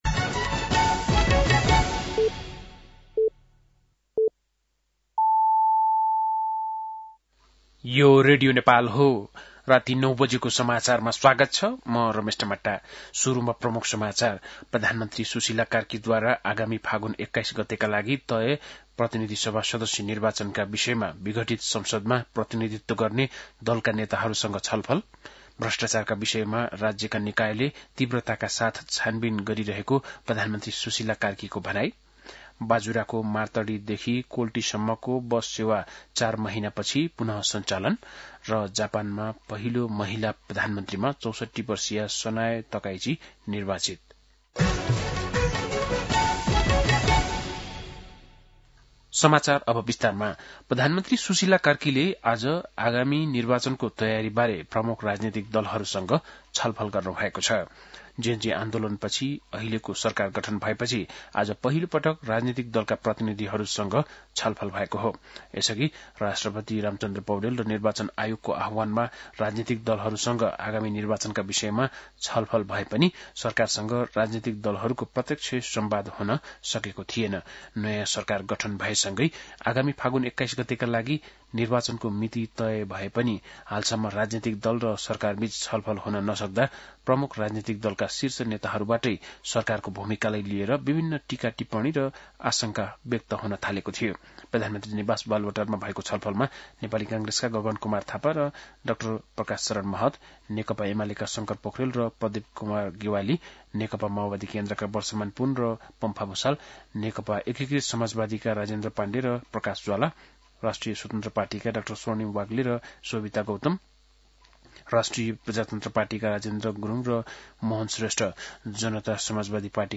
बेलुकी ९ बजेको नेपाली समाचार : ४ कार्तिक , २०८२
9-pm-nepali-news-7-04.mp3